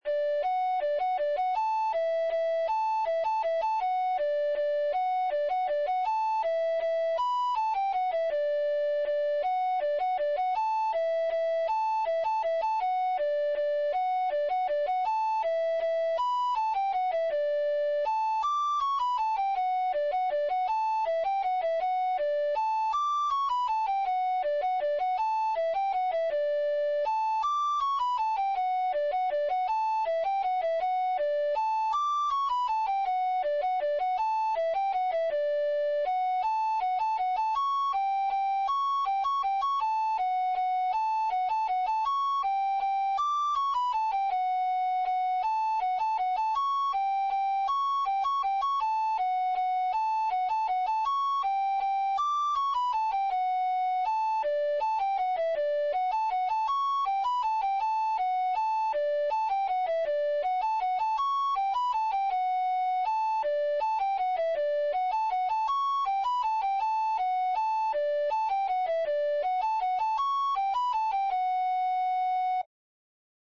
Mazurcas – Pezas para Gaita Galega
Etiqueta: Mazurcas
Primeira voz